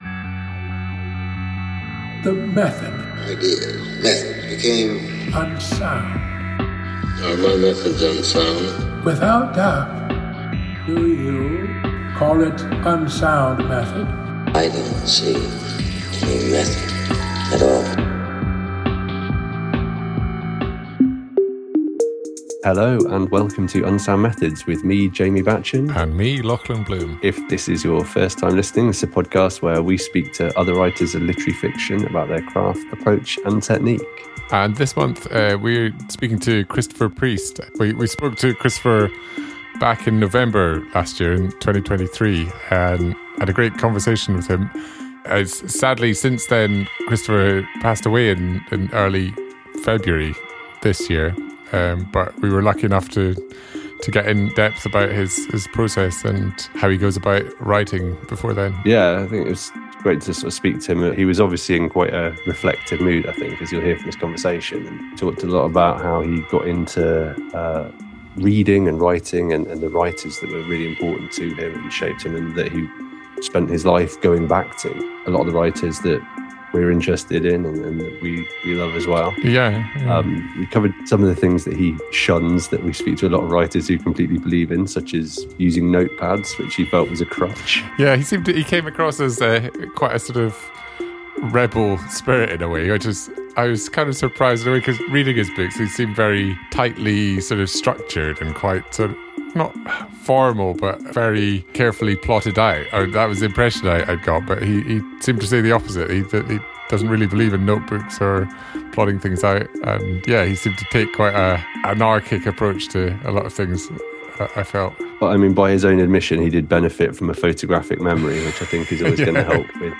This month’s episode is our chat with the late Christopher Priest, who sadly passed away on 2nd February 2024. In what will have been one of his last interviews, we spoke to Christopher on 3rd November 2023, where he talked us through his development as a writer, his skepticism about using notebooks, dealing with dreadful editors, not writing for nine years, and how writing is like walking to Doncaster.